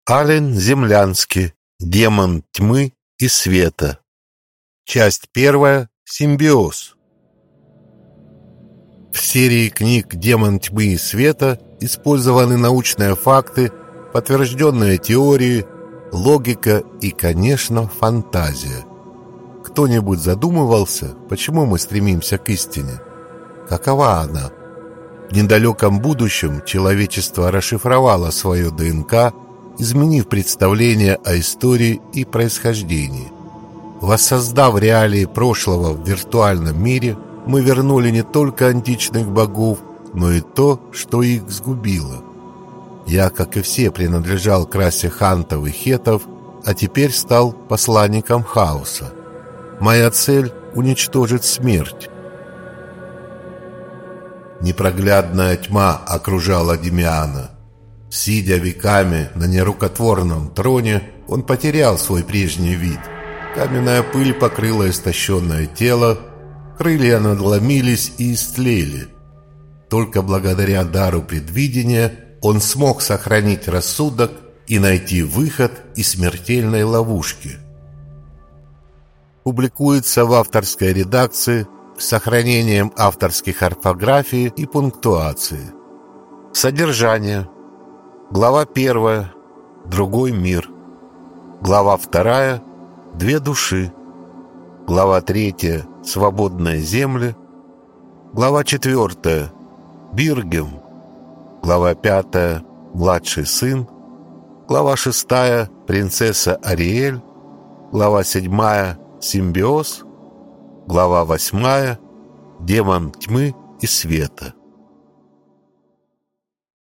Аудиокнига Демон тьмы и света. Часть первая. «СИМБИОЗ» | Библиотека аудиокниг